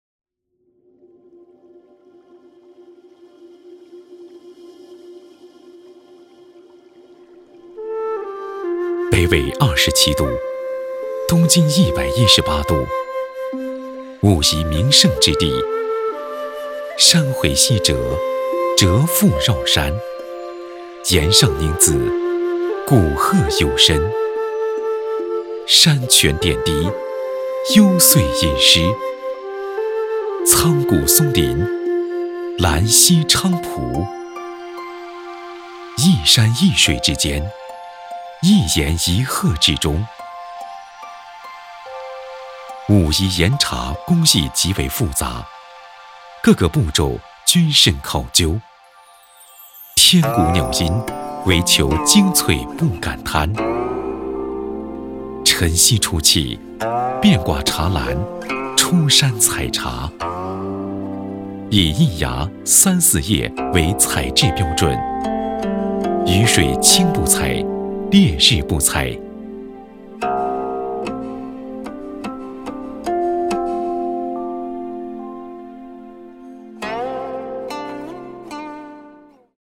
淘声配音网，专题，宣传片配音，专业网络配音平台 - 淘声配音网配音师男国语203号 大气 沉稳 厚重
配音风格： 大气 沉稳 厚重